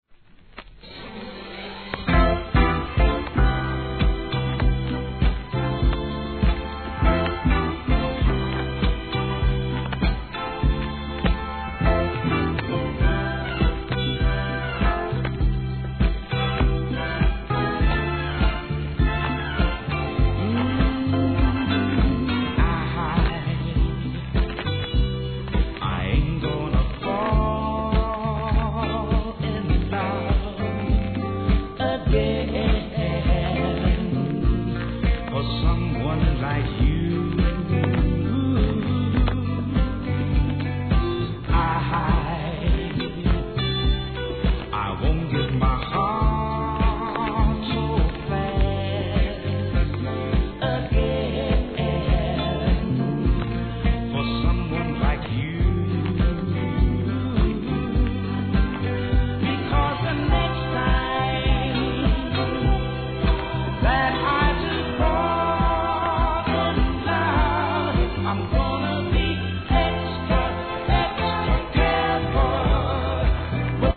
1. SOUL/FUNK/etc...
好甘茶ソウル♪ B/W